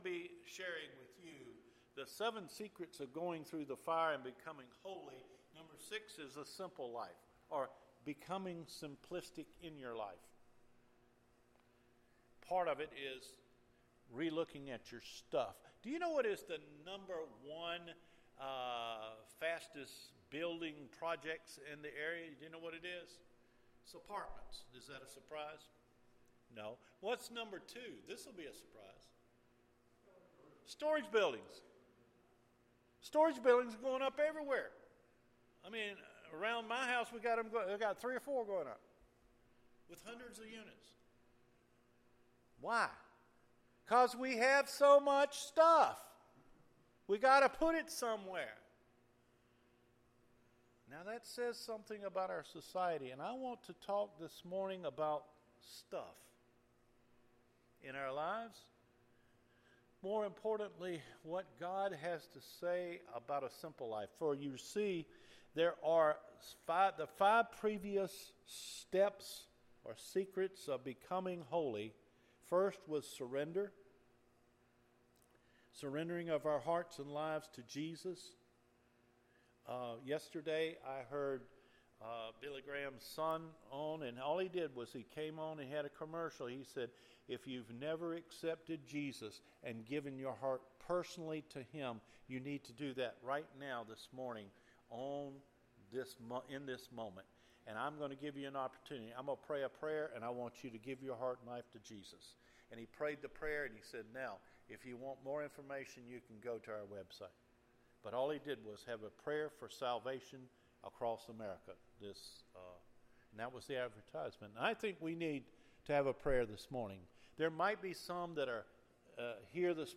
THE SIMPLE LIFE (6TH STEP) – AUGUST 16 SERMON – Cedar Fork Baptist Church